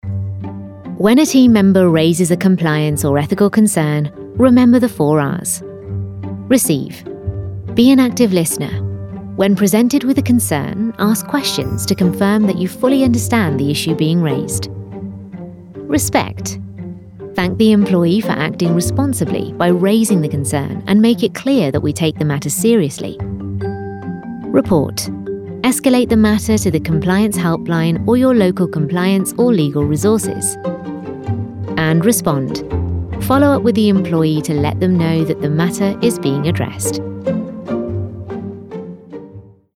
Anglais (Britannique)
Naturelle, Enjouée, Urbaine, Amicale, Chaude
E-learning